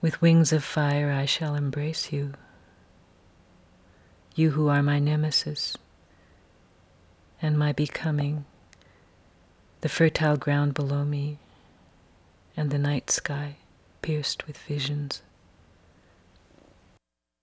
Processing: Stereo KS resonators, delay = 457/305 (C/G), input = 125->90->125, feedback =960, then up at end